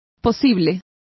Complete with pronunciation of the translation of prospective.